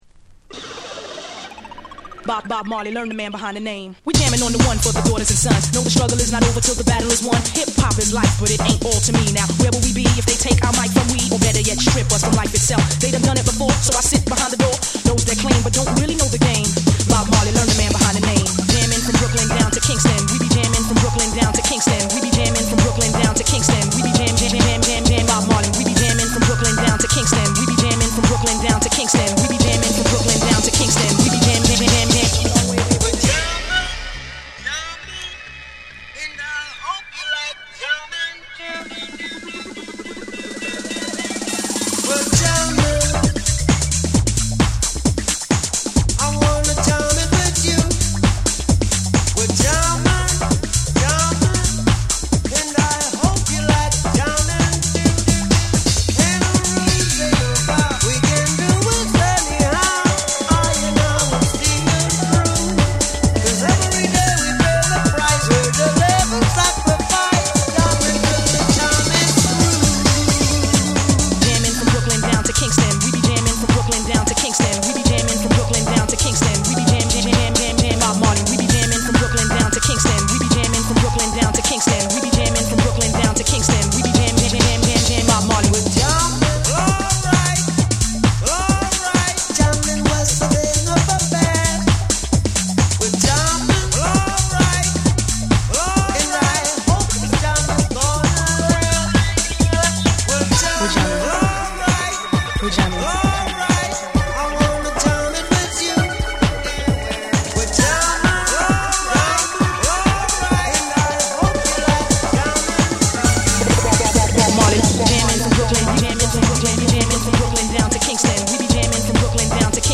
フロア映えするダンス・ナンバーにリミックス！
REGGAE & DUB / TECHNO & HOUSE